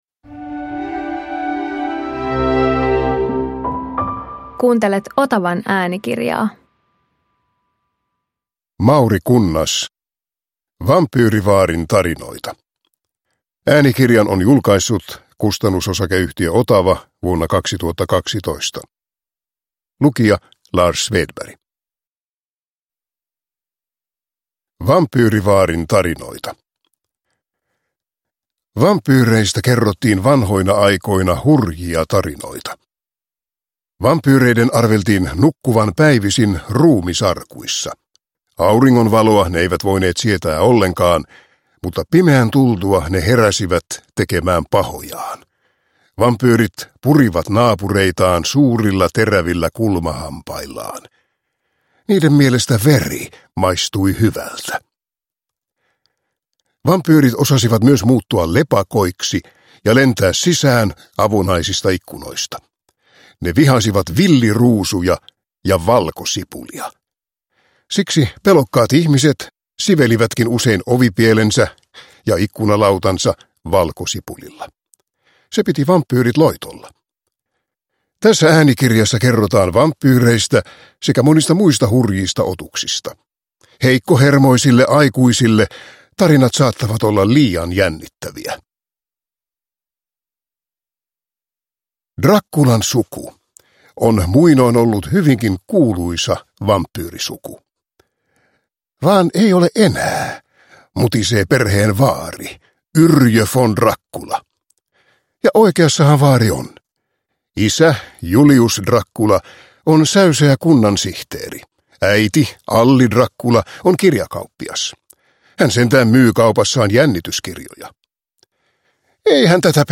Vampyyrivaarin tarinoita – Ljudbok